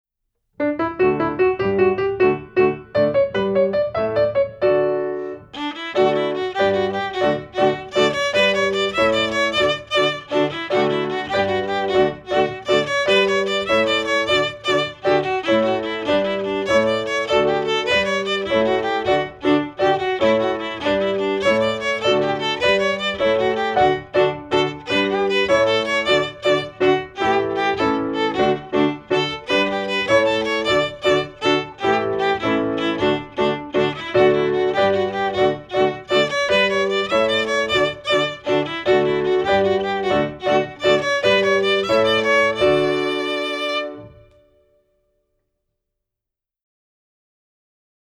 Voicing: Viola